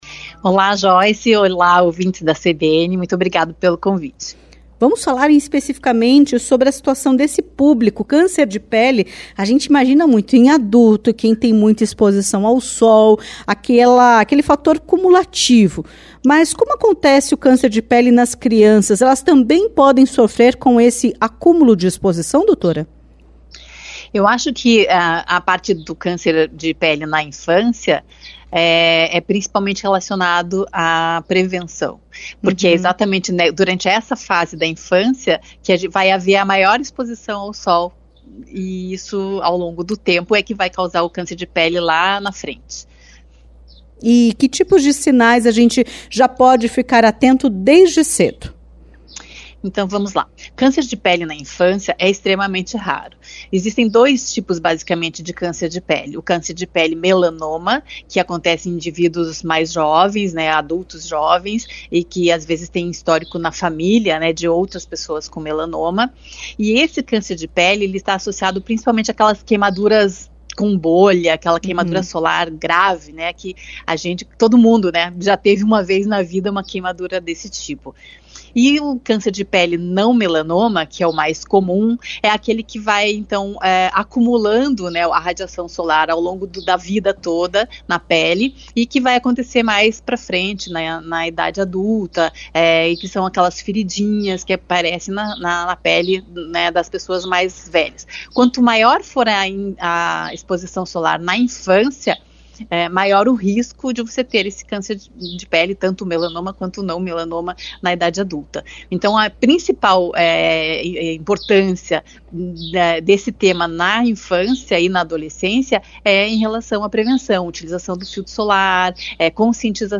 Entrevista-25-11.mp3